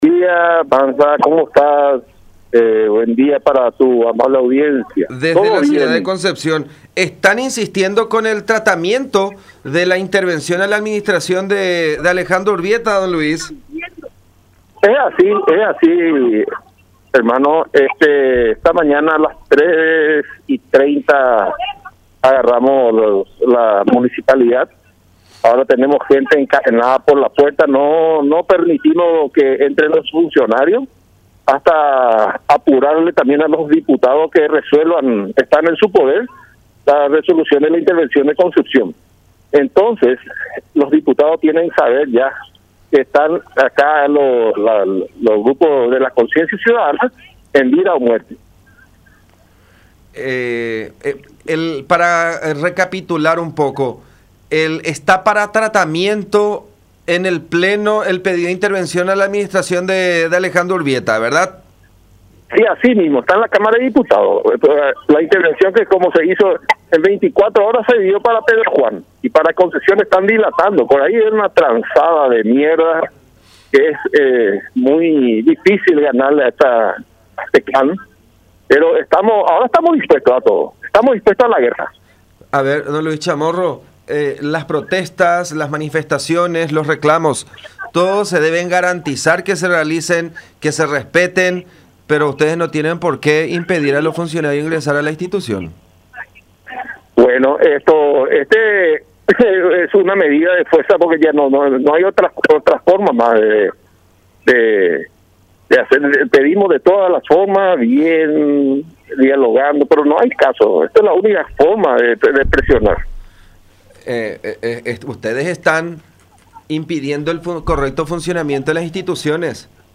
uno de los manifestantes, en contacto con La Unión.